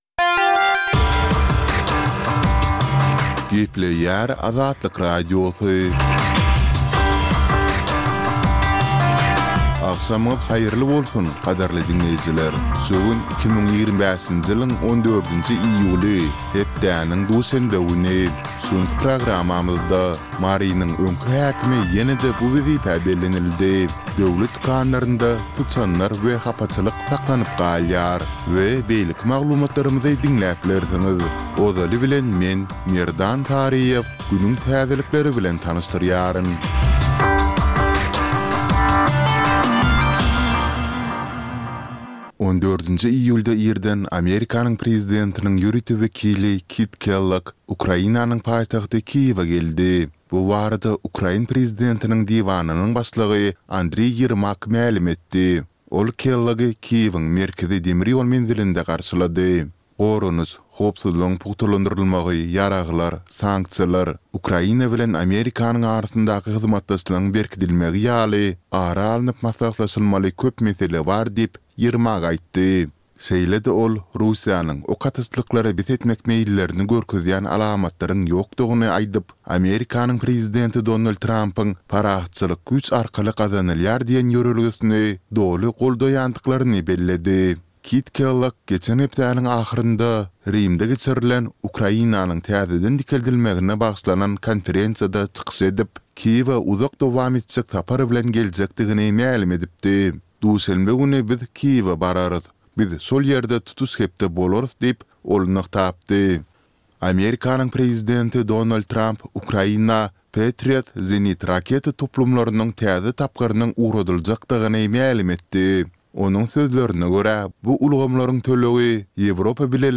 Dünýäniň dürli regionlarynda we Türkmenistanda şu günki bolan we bolup duran soňky wakalar barada gysgaça habarlar.